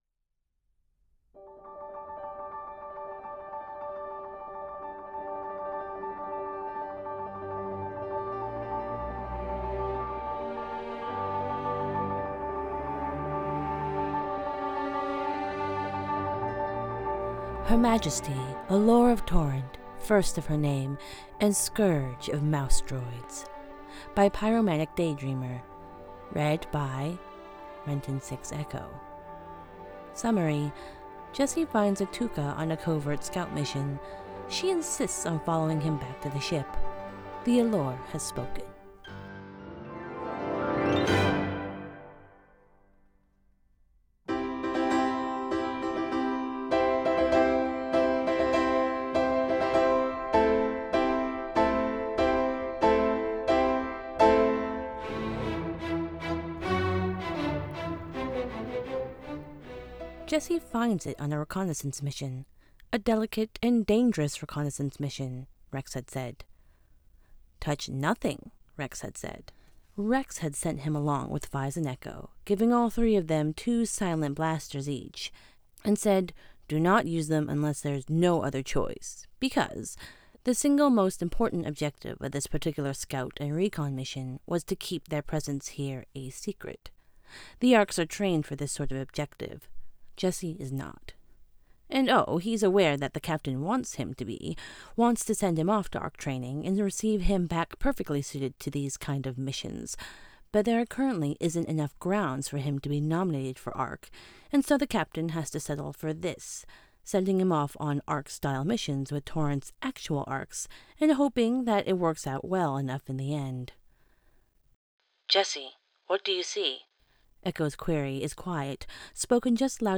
[Podfic] Her Majesty, Alor of Torrent, First of Her Name and Scourge of Mousedroids
Podfic-Her-Majesty-Alor-of-Torrent-First-of-Her-Name-and-Scourge-of-Mousedroids.m4a